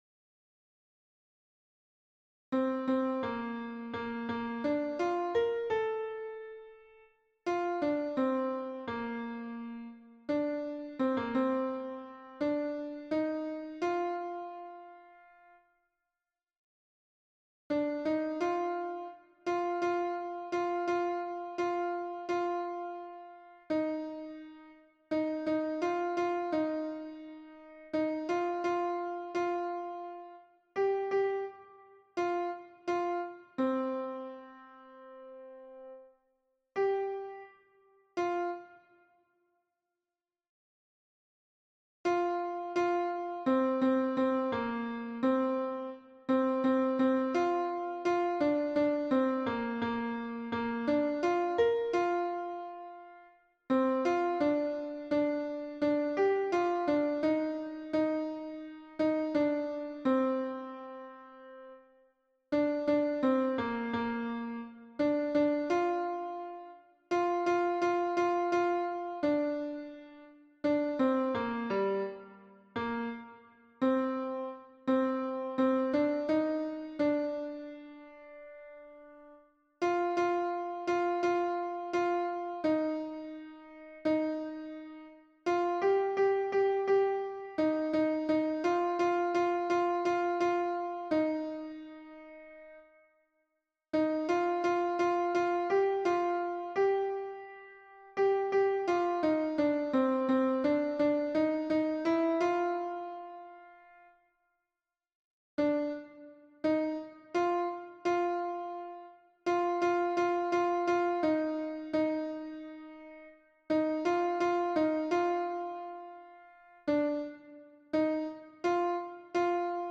MP3 version piano - vitesse réduite pour apprentissage
Alto 1